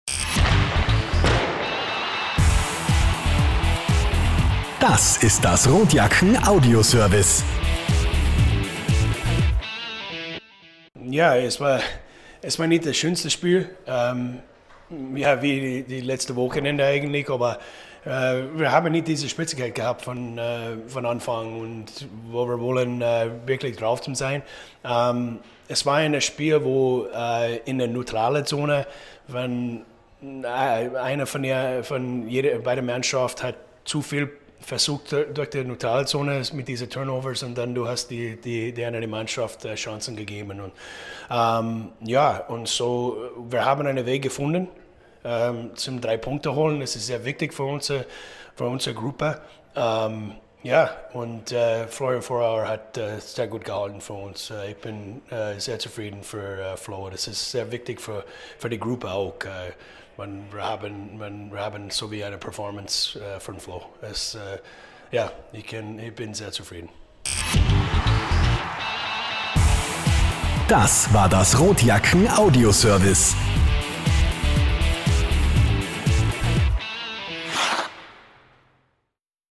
Post Game-Kommentar von Head Coach